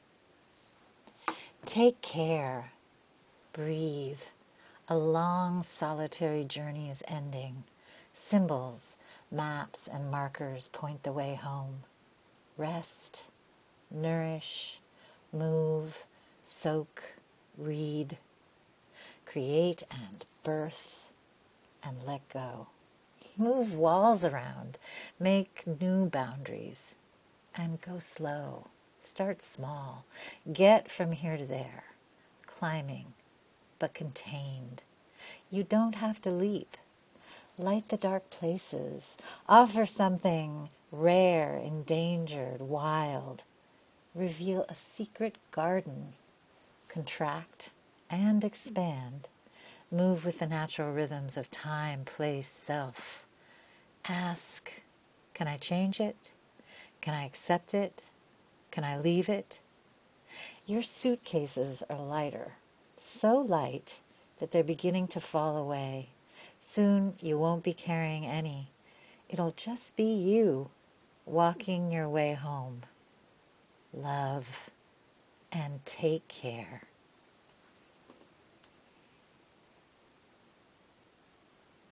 I also loved hearing you read this:~) It made it even more powerful!!